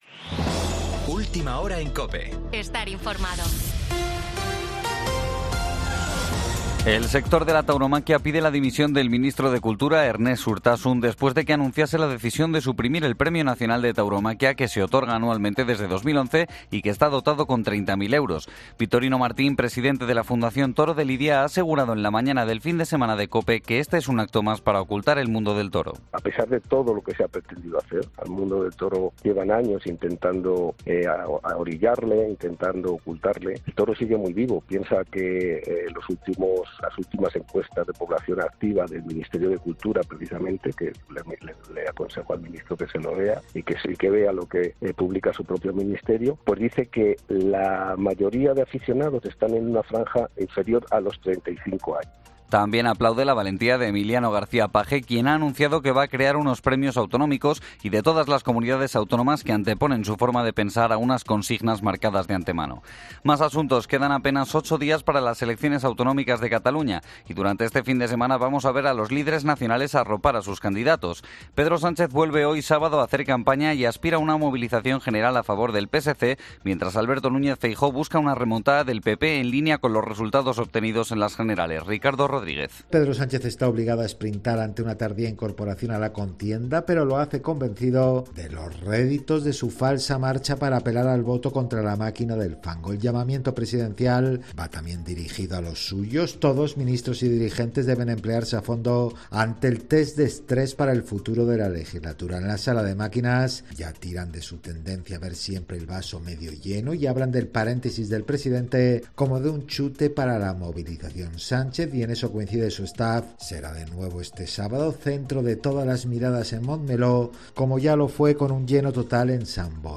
Boletín 9.00 horas del 27 de abril de 2024